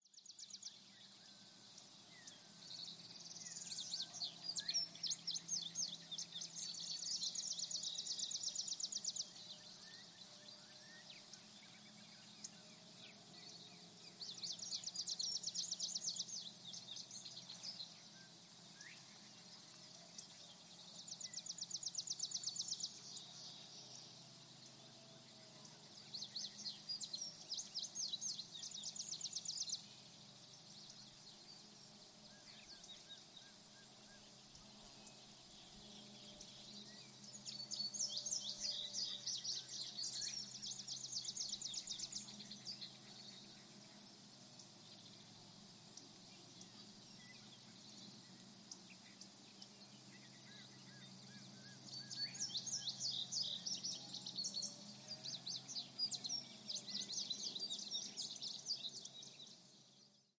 field-sounds-2.mp3